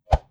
Close Combat Swing Sound 74.wav